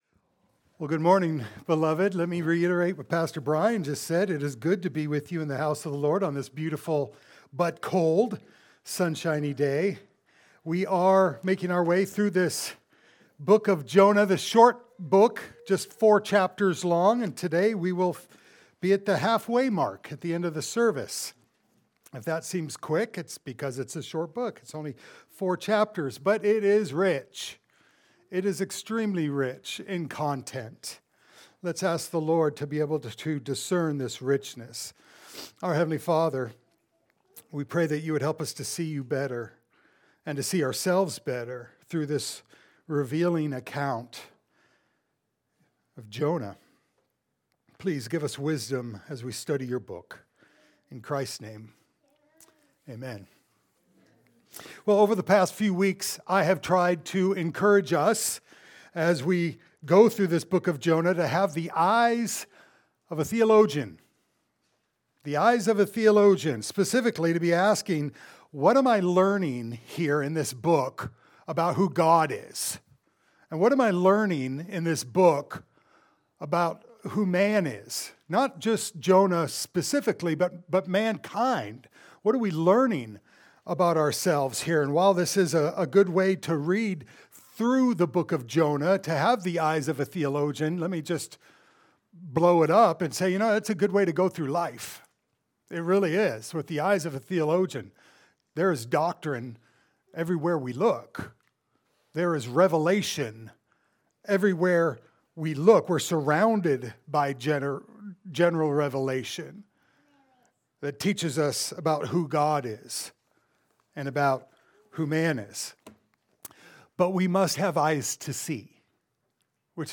Jonah Passage: Jonah 1:17-2:10 Service Type: Sunday Service « “Jonah…